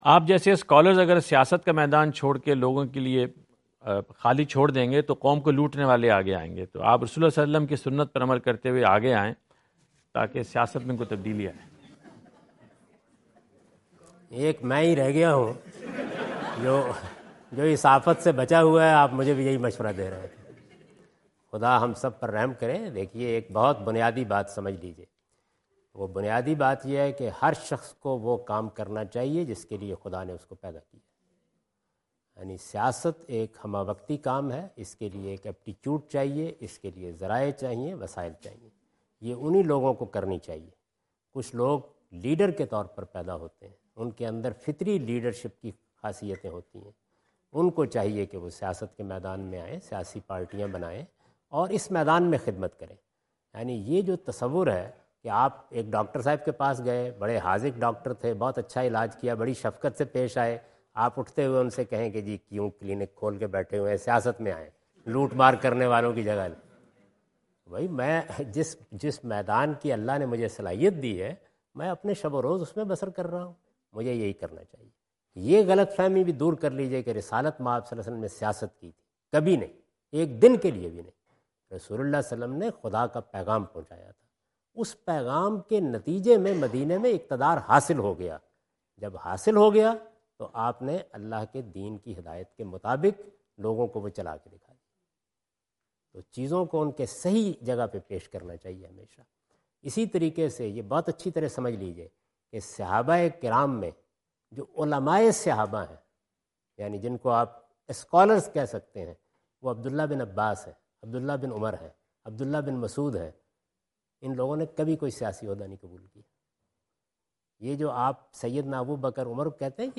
جاوید احمد غامدی اپنے دورہ امریکہ2017 کے دوران فلیڈیلفیا میں "کیا مذہبی علما کو سیاست سے دور رہنا چاہیے؟" سے متعلق ایک سوال کا جواب دے رہے ہیں۔